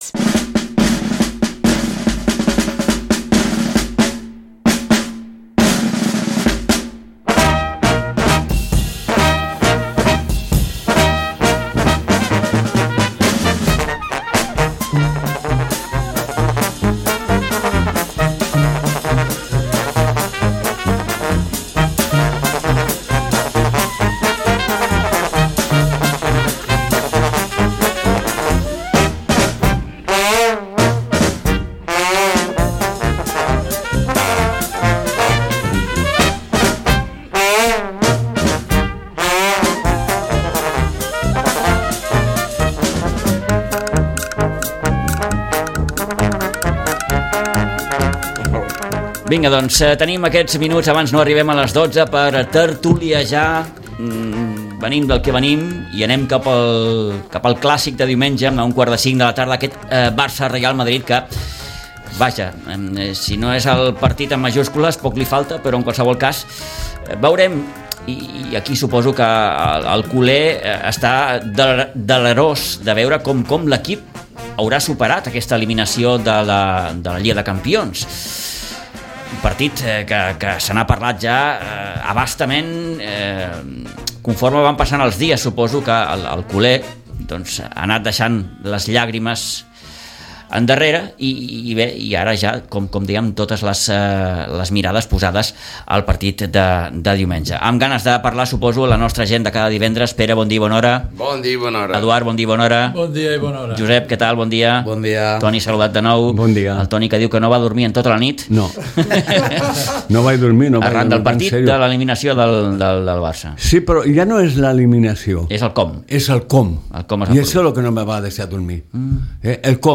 La tertúlia esportiva